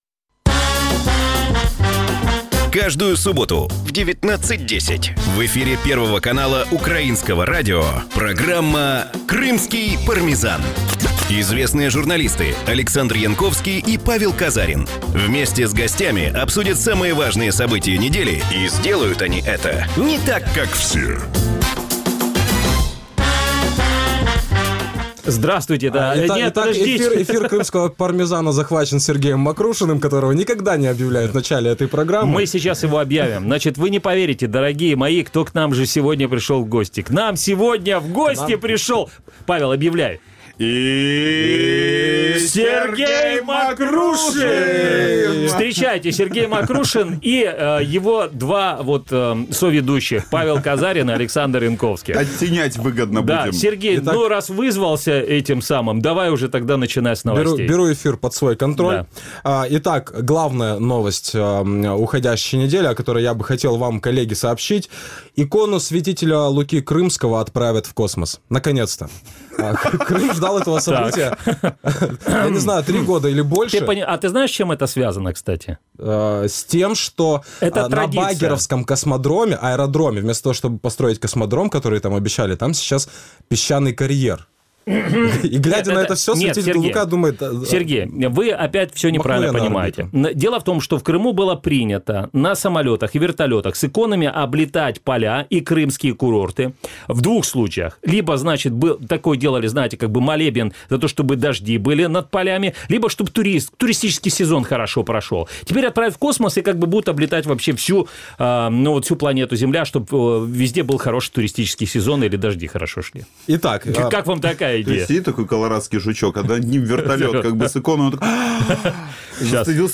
Програма звучить в ефірі Радіо Крим.Реалії. Час ефіру: 18:10 – 18:40 (19:10 – 19:40 у Криму).